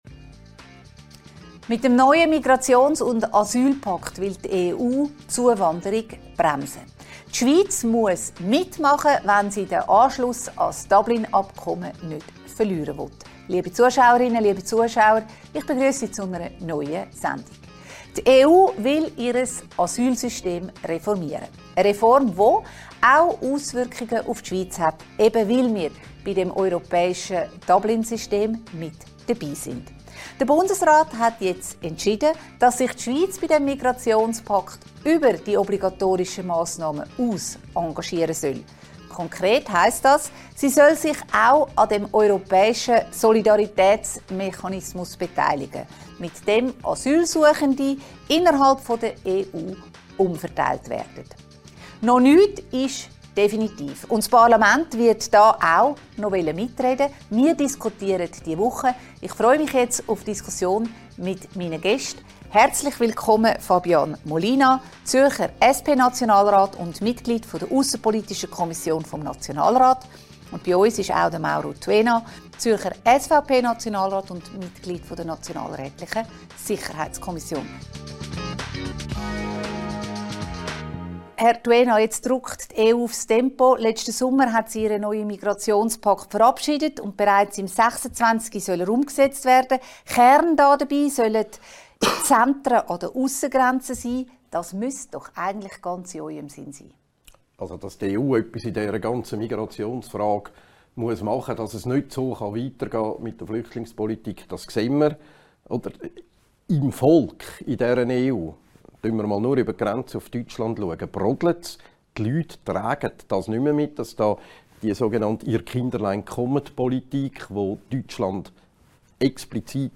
diskutiert mit den beiden Zürcher Nationalräten Fabian Molina, SP und Mauro Tuena, SVP den jüngsten Entscheid des Bundesrats zum EU-Migrationspakt.